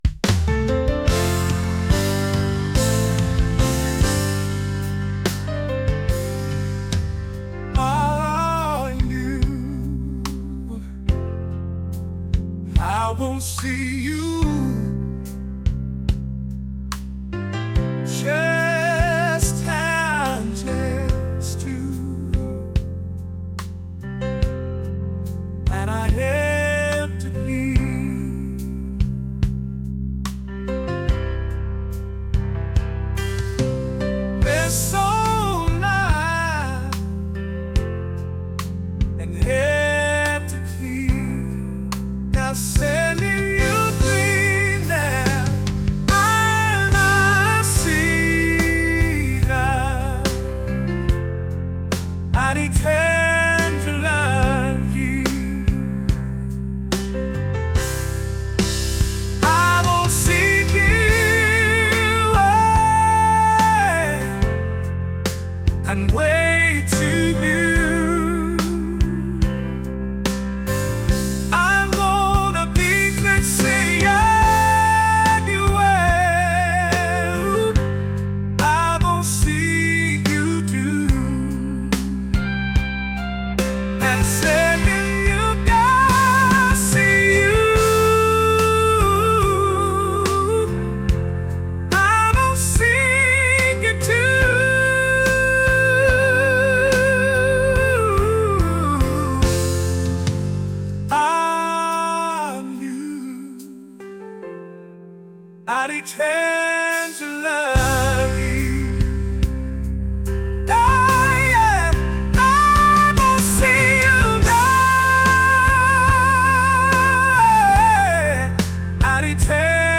soulful